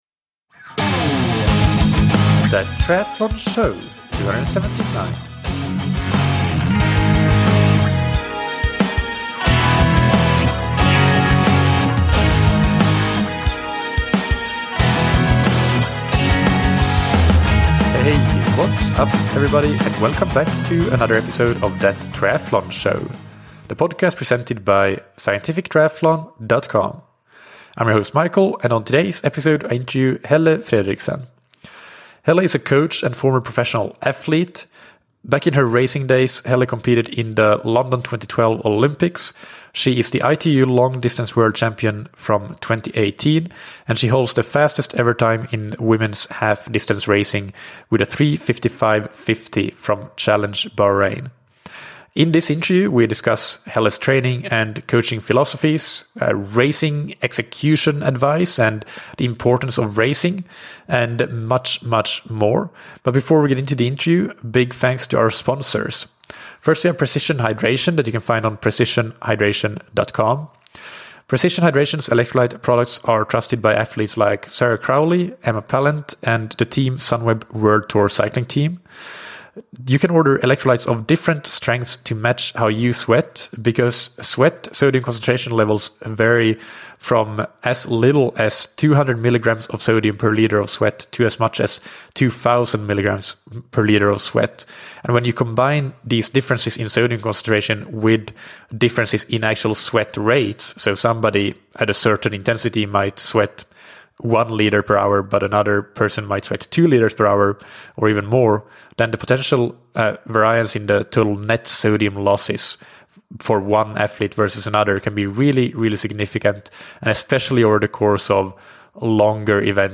In this interview, we discuss Helle's training and coaching philosophies, as well as the importance of racing to develop, and how to get the best out of yourself on race day.